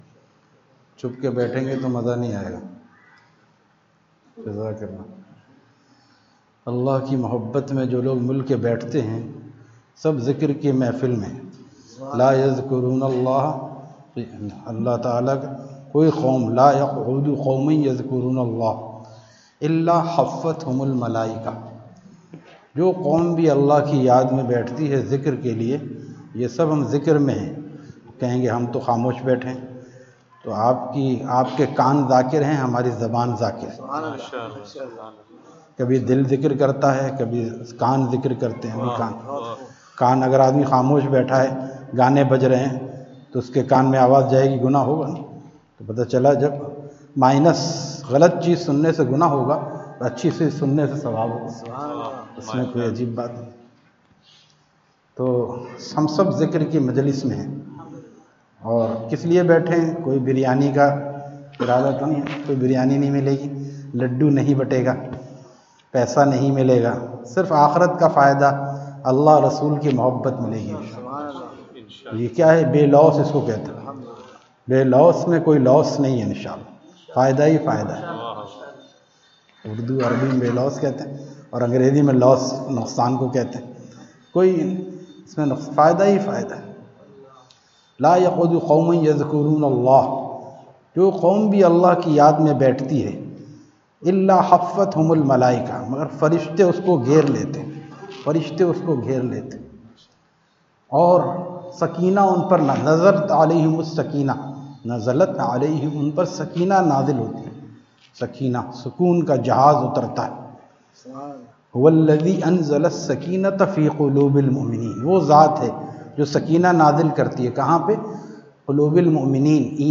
Bayan After Isha at Zahir Shah Masjid, Pathan Colony, Hyderabad